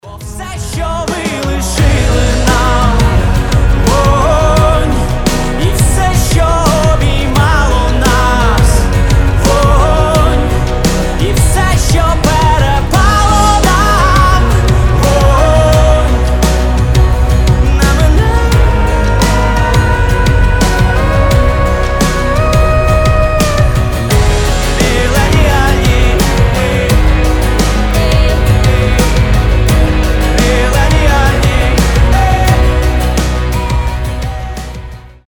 • Качество: 320, Stereo
грустные
Pop Rock
indie rock